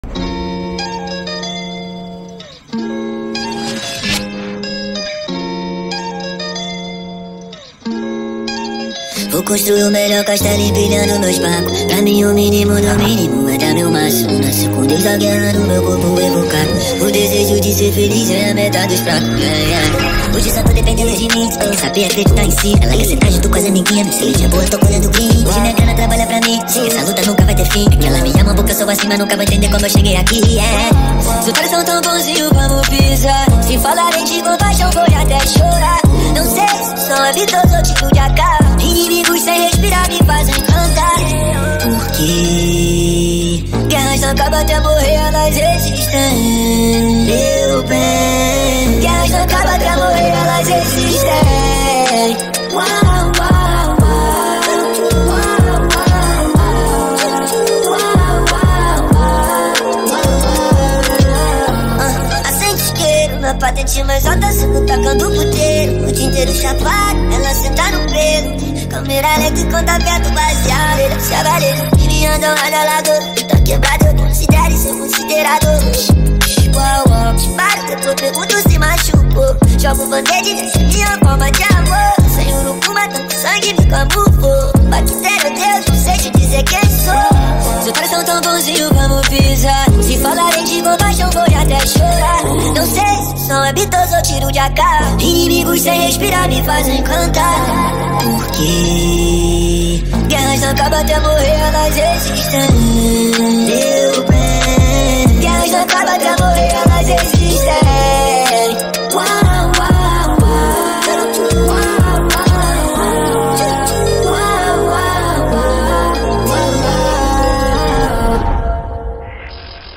2024-05-06 17:05:34 Gênero: Trap Views